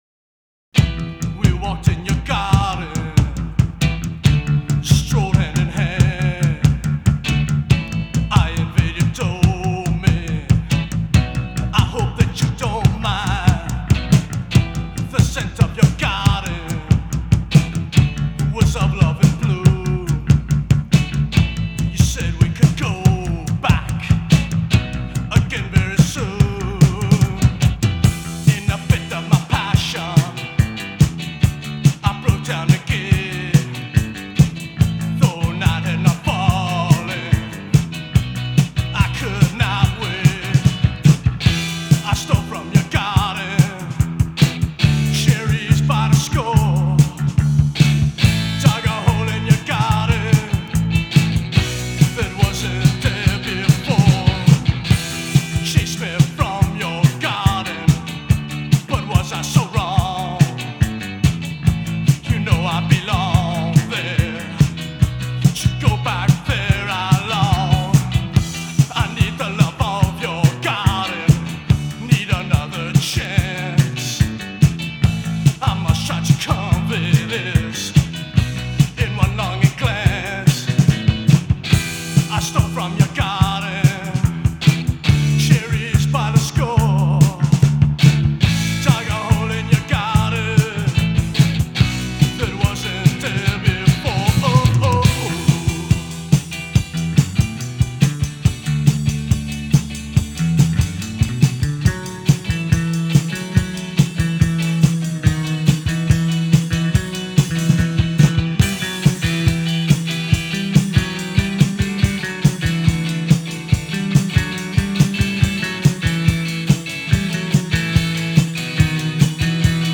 Power Pop
Voz, guitarra
Bajo, voces
Batería